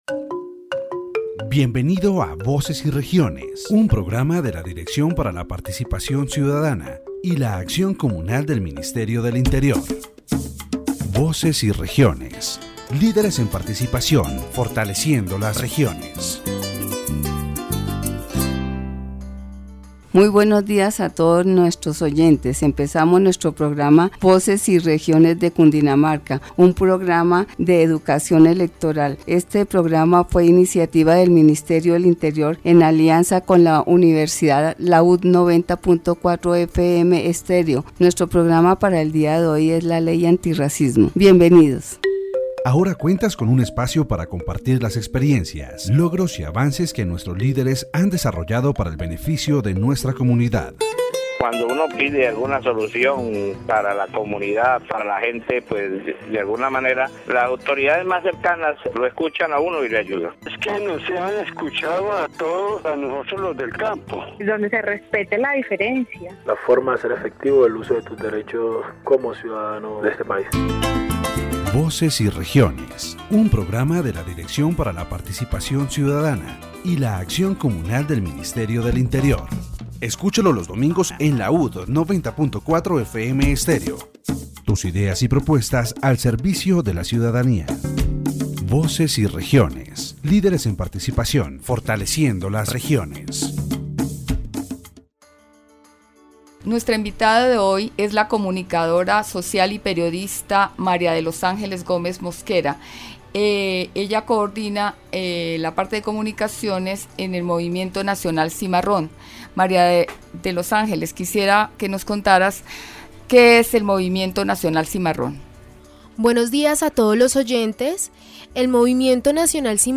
In this section of the Voces y Regiones program, the interviewee discusses the advocacy for the Afro-Colombian population as subjects of rights, emphasizing the need for their recognition within the country.